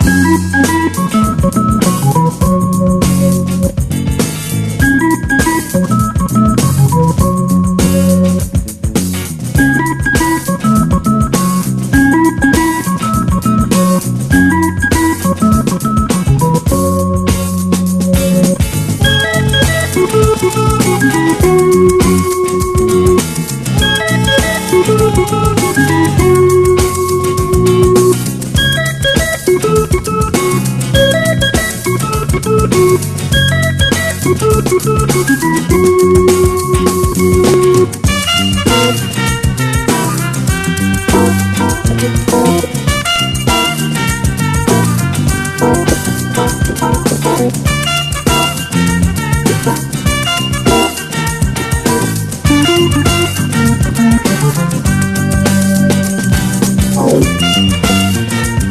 ROCK / 90''S～ / LOUNGE / INSTRO / SURF
97年ネオ・ラウンジ～スパイ・ムーヴィー～TIKI～サーフなインストロ！
スパイ映画のようなパーカッシヴなジャズ・ラウンジや、サーフ〜エキゾ調まで多彩。